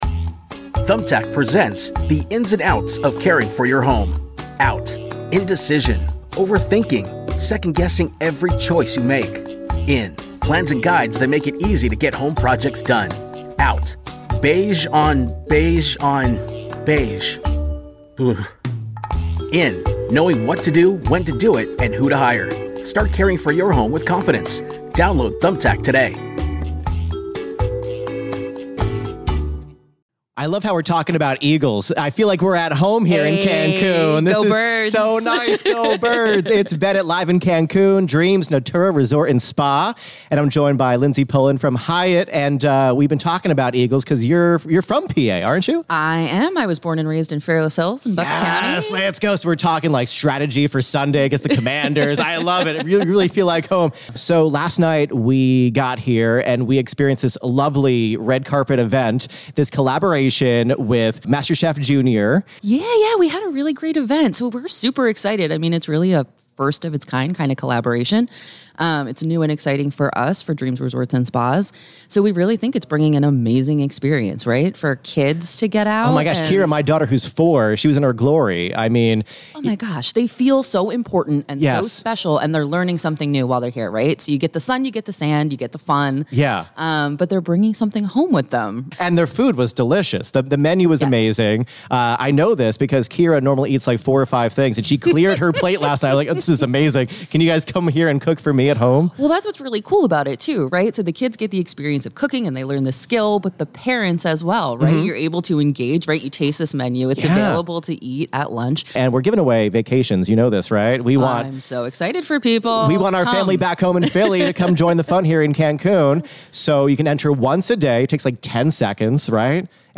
broadcasting live from Dreams Natura in Cancun, Mexico!